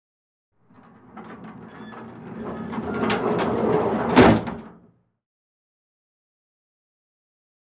Big Rolling Wood Door Close.